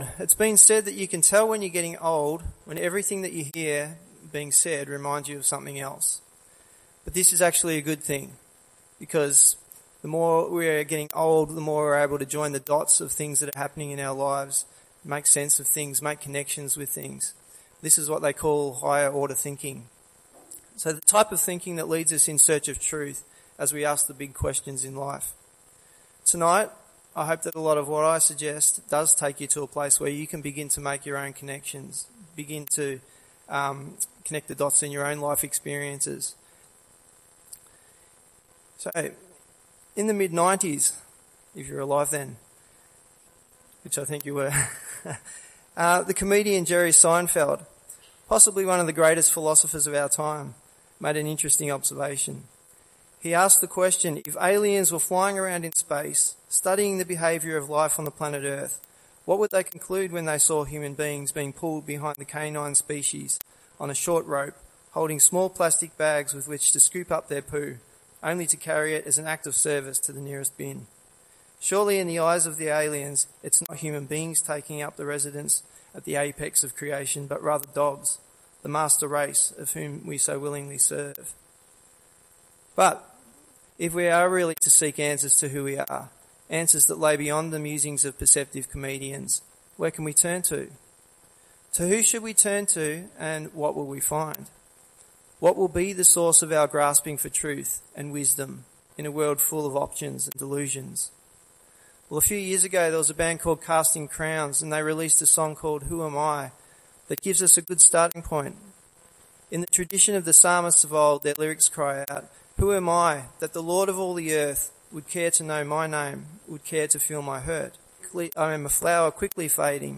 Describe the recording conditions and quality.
I recently gave a small talk with these questions in mind and to introduce some of what my book is all about. Here is a section of my talk for those who couldn’t make it.